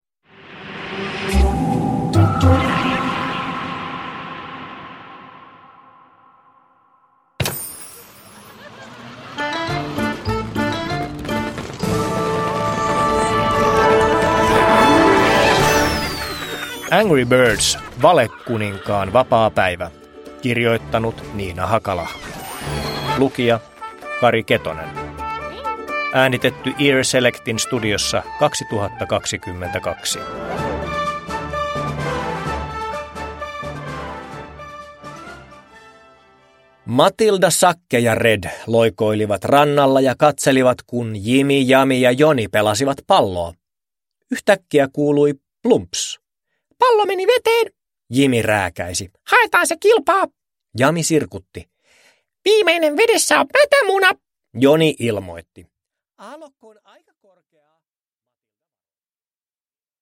Angry Birds: Valekuninkaan päivä (ljudbok) av Niina Hakalahti